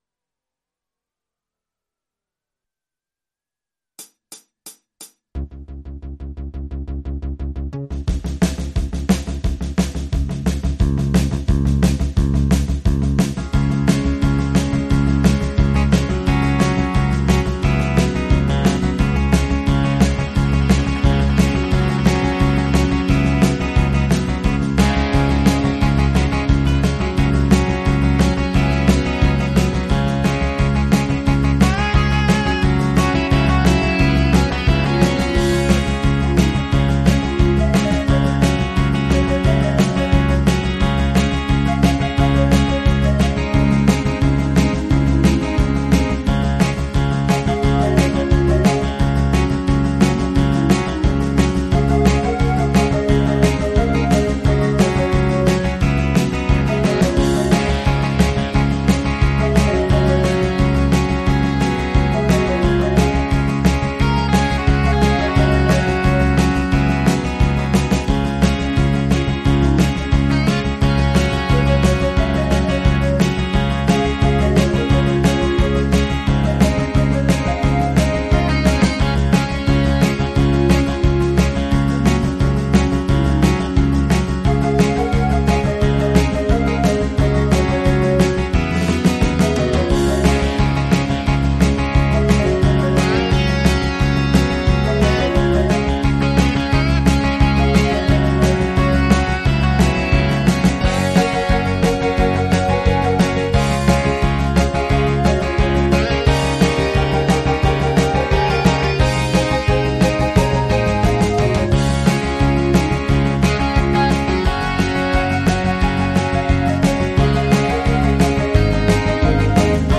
au format MIDI Karaoke pro.